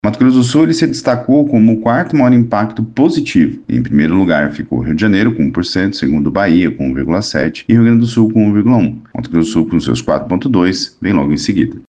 Em entrevista à FM Educativa MS 104,7